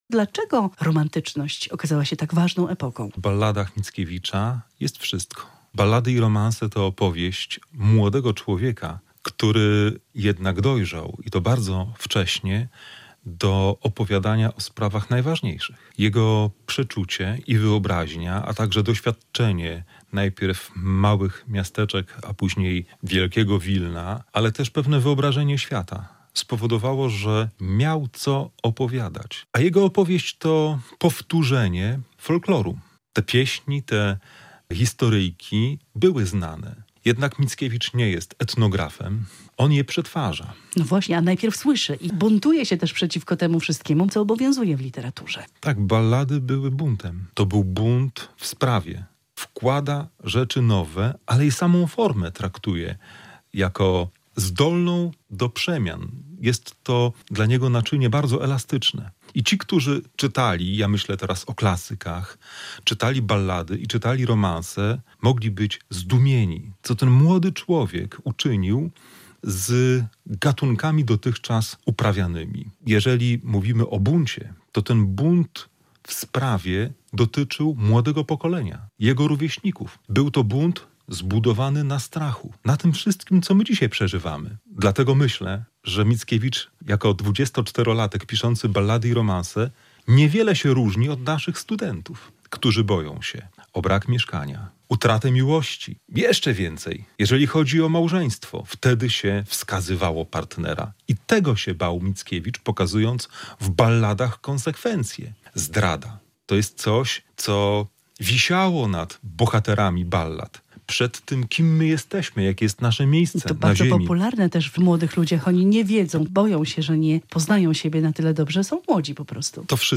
Studio Radia Bialystok